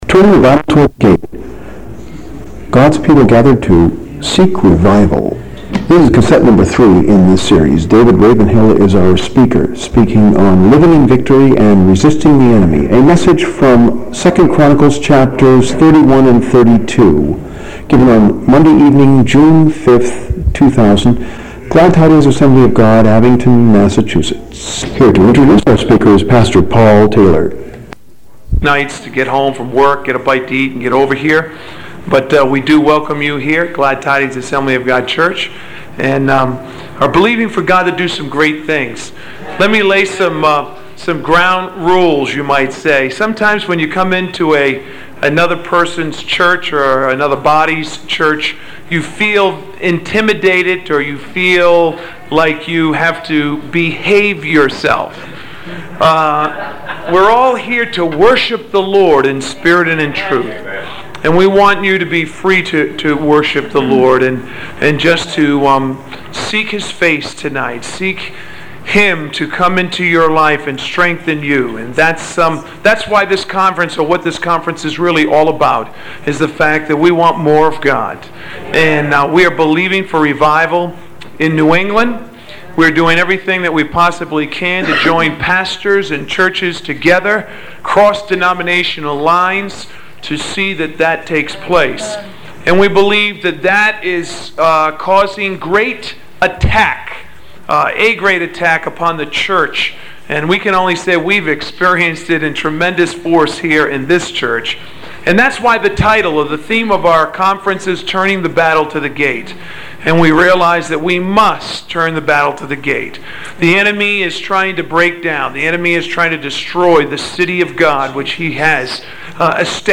Glad Tidings Spring Convention B1